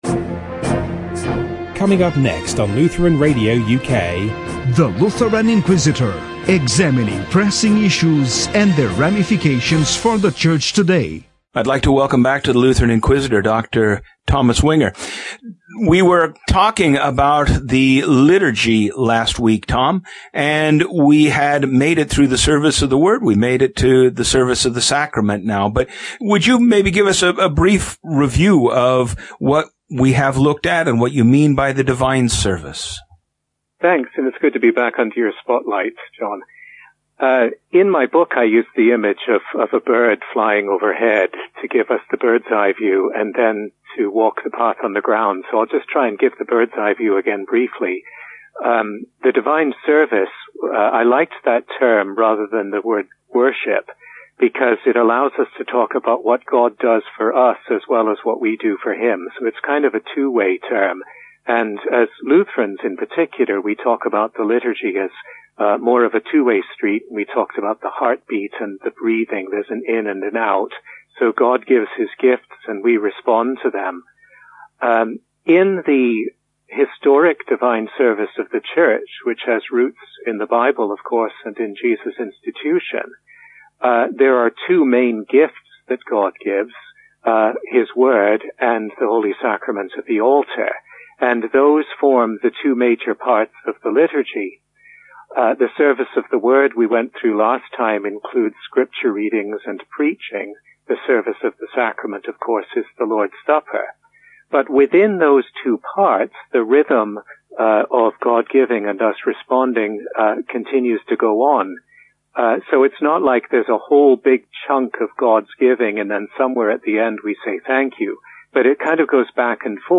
Lutheranism 101: Worship Interview, Part 4